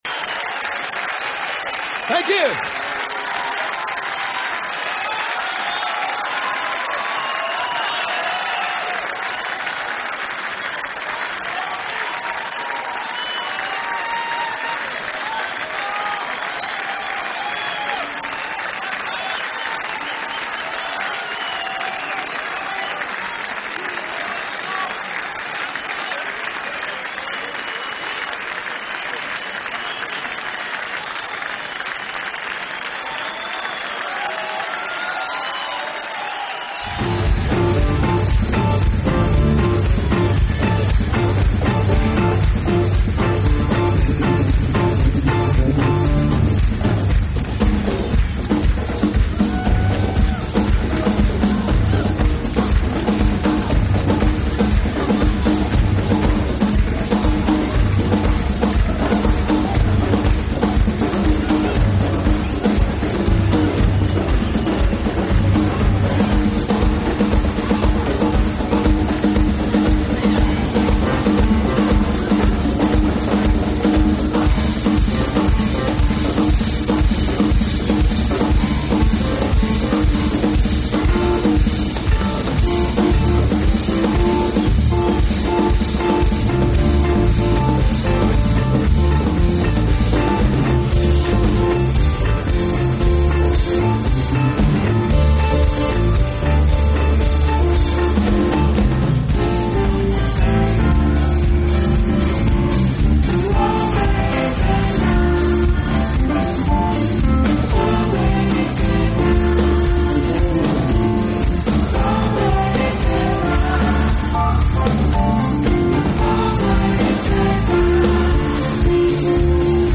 Keyboards
Guitar
Bass and Vocals
Drums